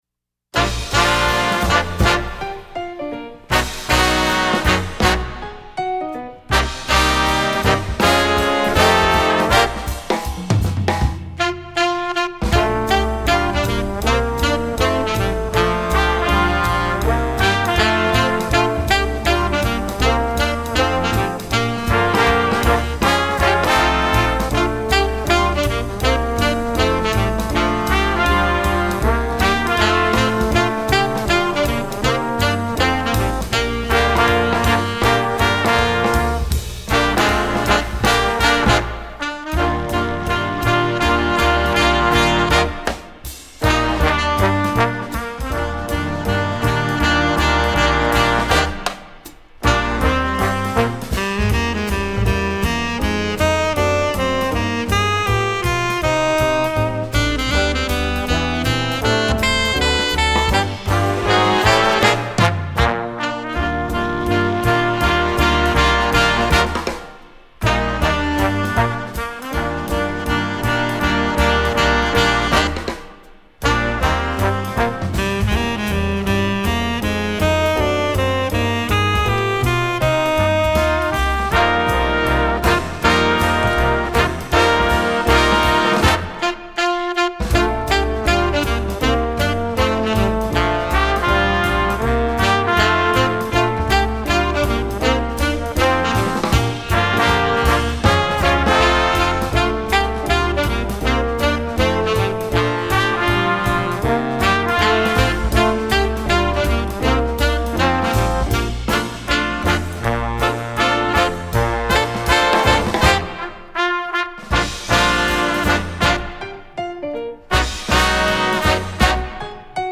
jazz, secular